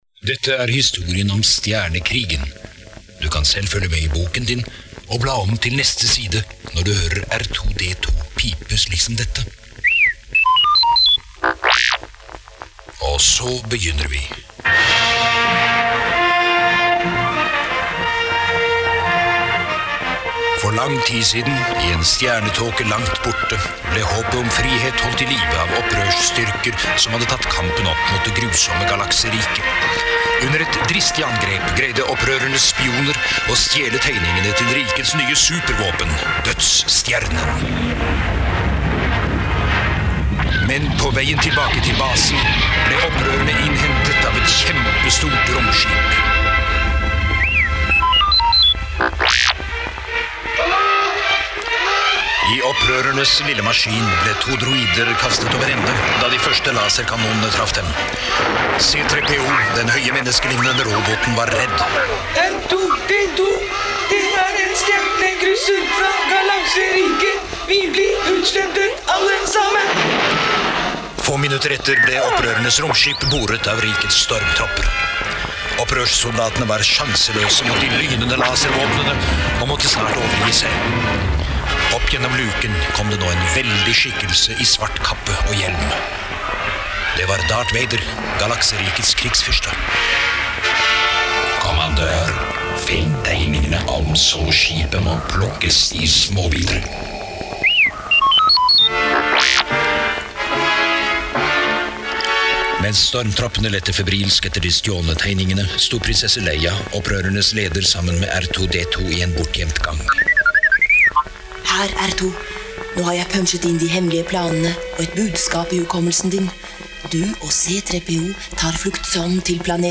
Voice actor credits as follows: Story Driver
starwars_audiobook.mp3